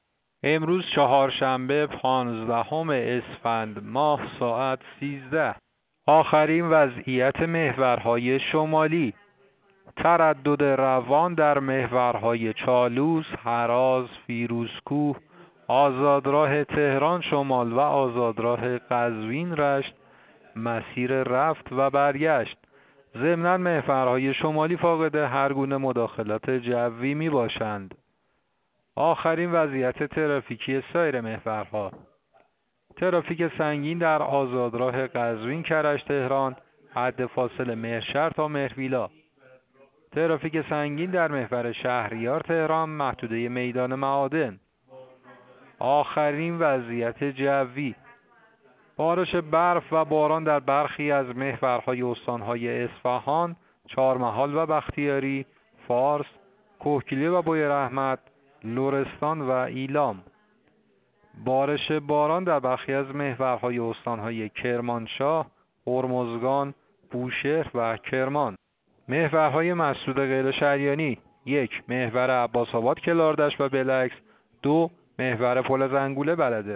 گزارش رادیو اینترنتی از آخرین وضعیت ترافیکی جاده‌ها ساعت ۱۳ پانزدهم اسفند؛